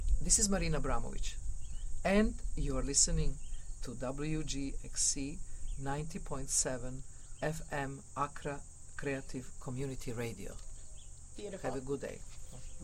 Marina Abramović WGXC Station Id (Audio)
MarinaAbramovicWGXCstationID.mp3